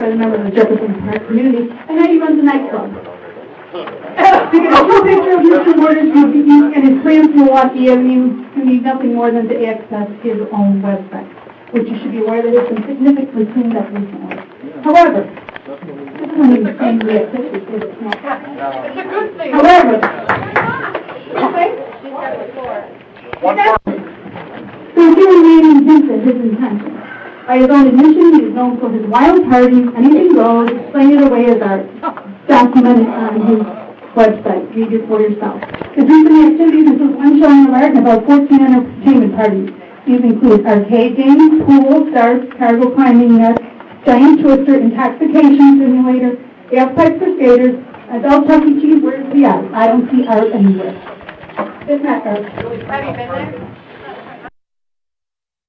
Realaudio clips from the 6-27 zoning meeting.